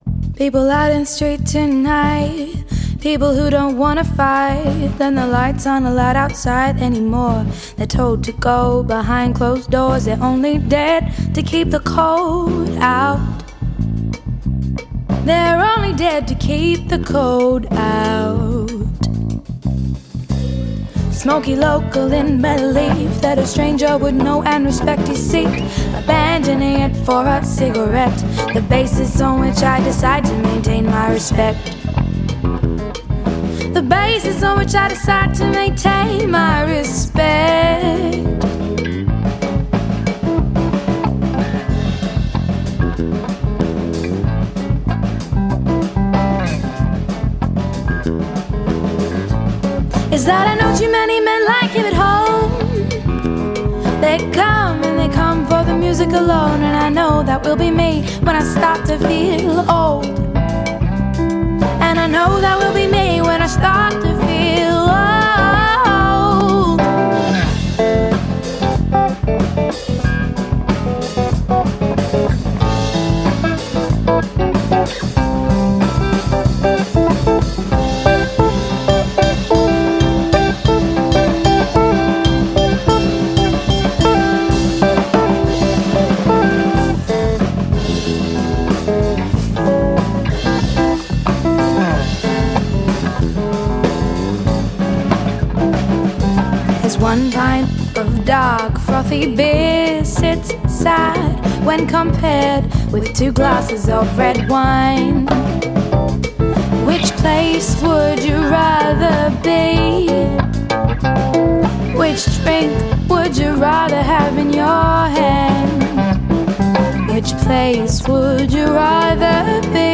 Melbourne Australia based singer-songwriter